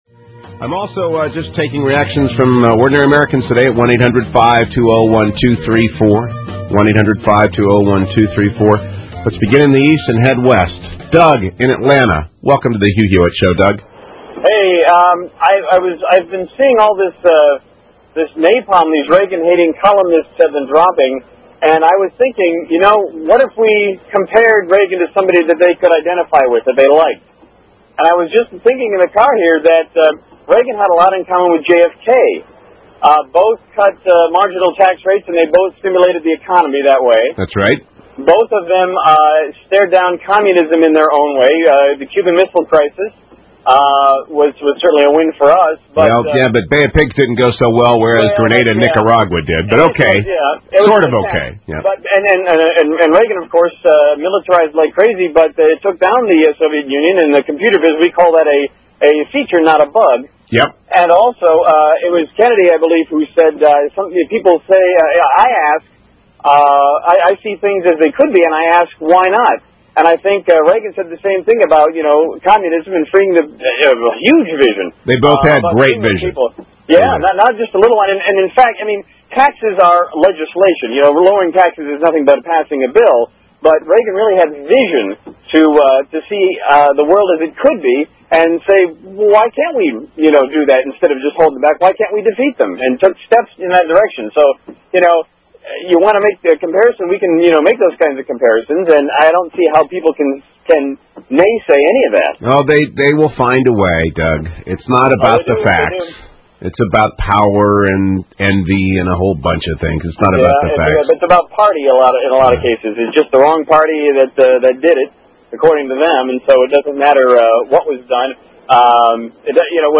On today's "Considerettes Radio", I explained to Hugh Hewitt 3 ways Reagan and JFK were similar, and in major ways, no less.
"Considerettes Radio" on The Hugh Hewitt Show (WGKA, Atlanta, GA) 6/9/2004 7:05pm EST (253K)